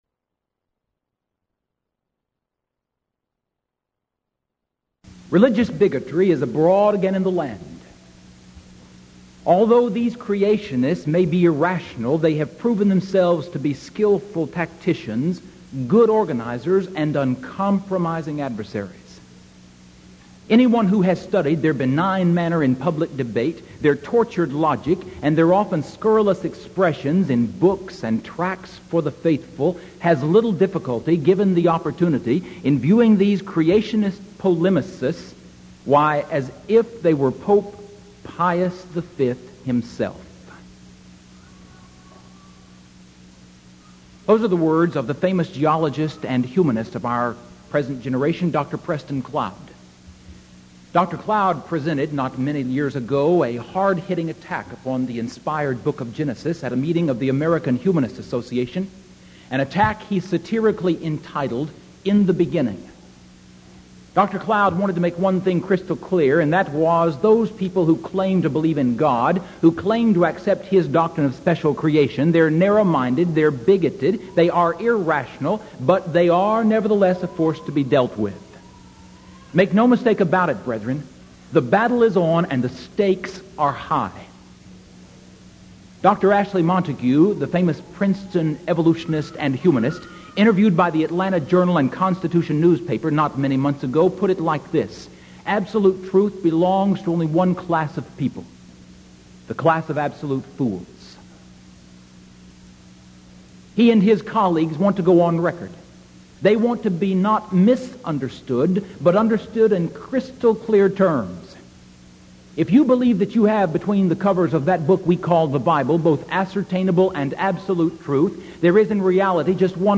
Title: Discussion Forum
Event: 1986 Denton Lectures Theme/Title: Studies in Galatians